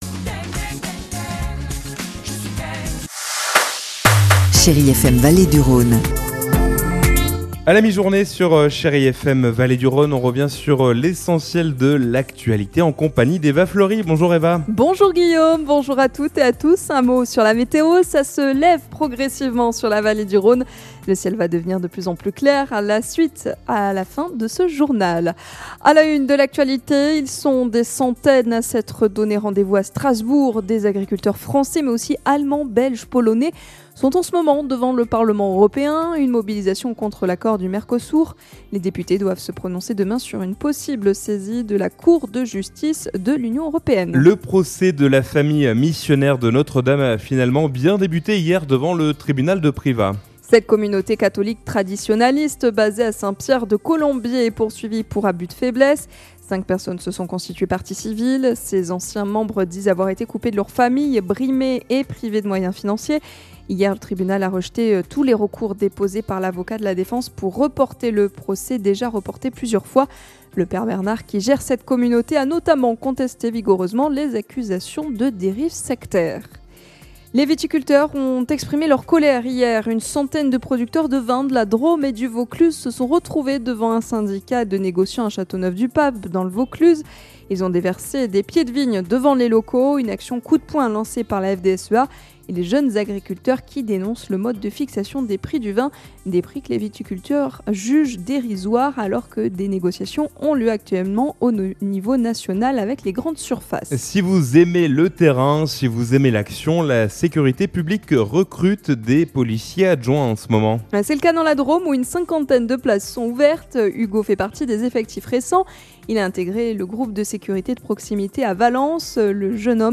Mardi 20 janvier : Le journal de 12h